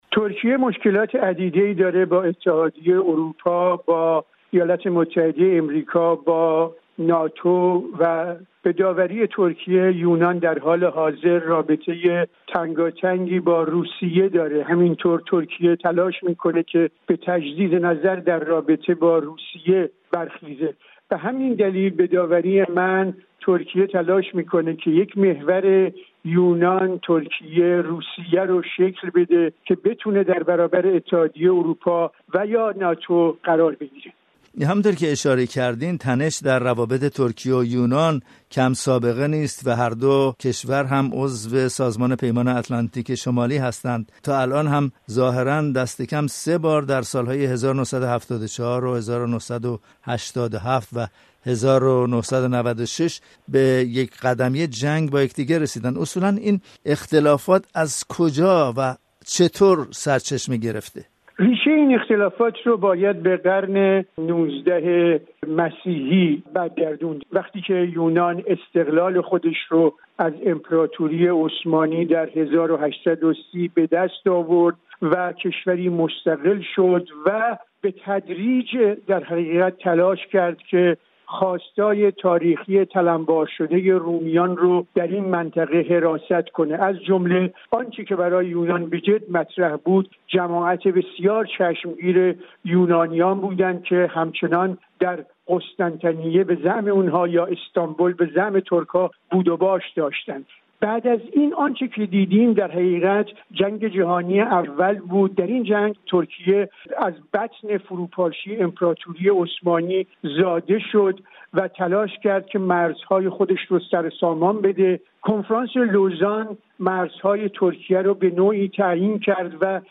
گفت وگو